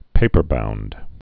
(pāpər-bound)